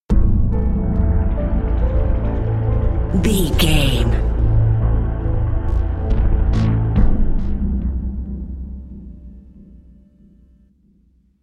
In-crescendo
Thriller
Aeolian/Minor
Slow
piano
synthesiser